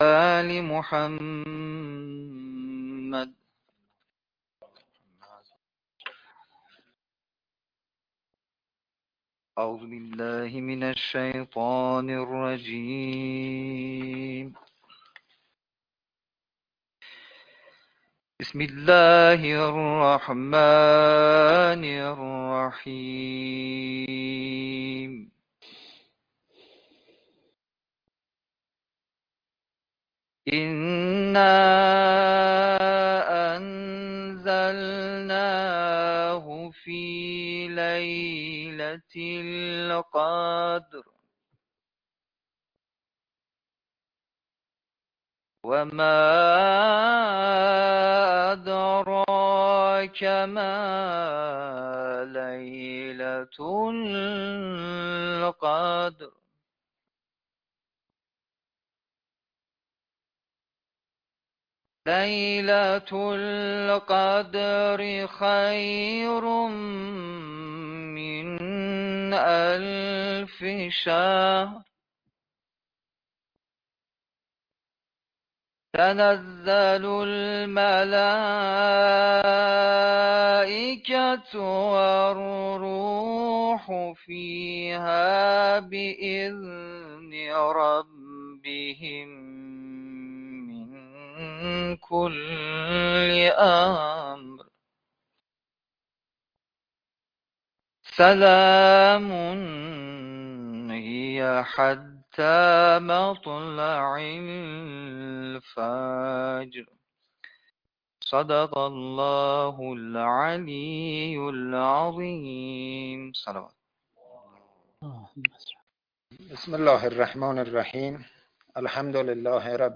کرسی ترویجی عرضه و نقد ایده علمی